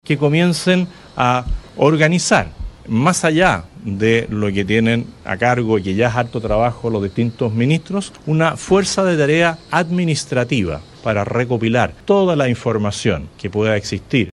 Pero eso no fue todo, ya que José Antonio Kast anunció una medida que incluso suena como advertencia a la próxima administración: la conformación de una “fuerza de tarea administrativa” que buscaría -en lo medular- revisar exhaustivamente la información recibida hasta ahora por cada cartera, sistematizarla y contrastarla una vez que el nuevo gobierno asuma formalmente sus funciones.